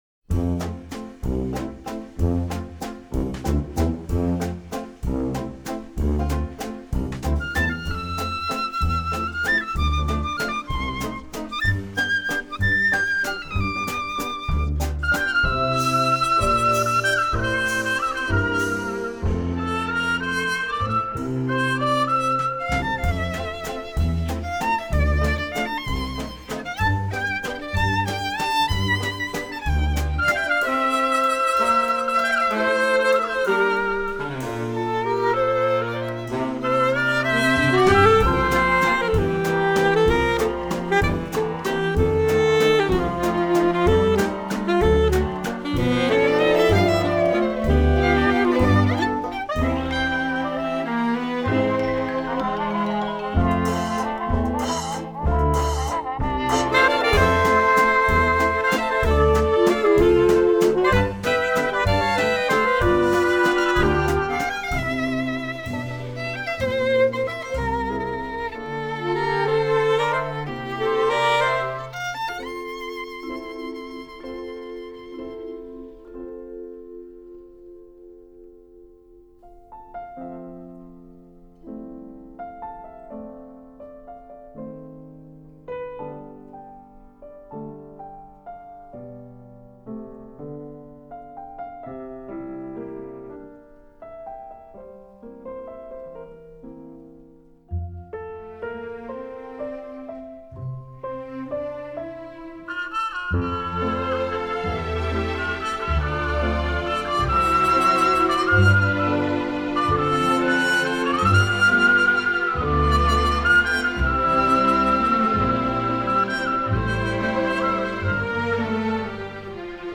2009   Genre: Soundtrack    Artist